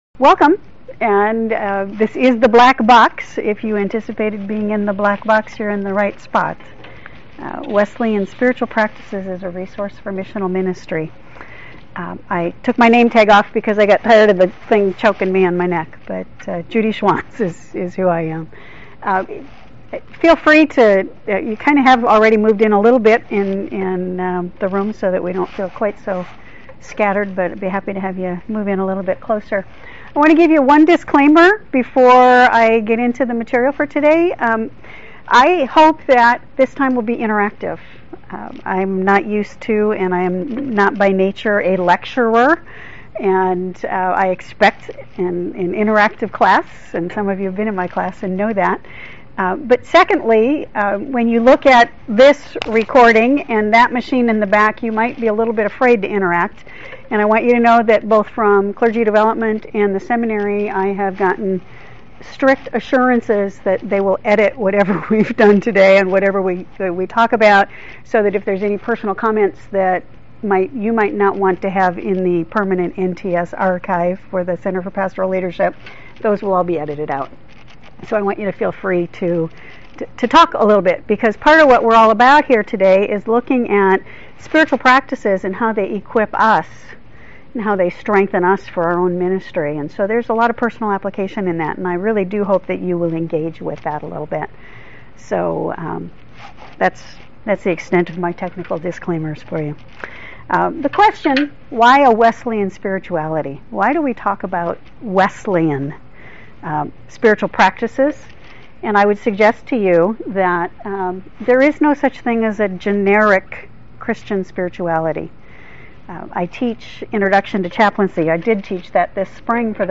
The life of a minister is demanding and can drain a person emotionally, spiritually, and even physically. This NTS-sponsored workshop identifies some of the resources of the Wesleyan tradition that provide spiritual sustenance for the minister and emotional health for long-term missional ministry.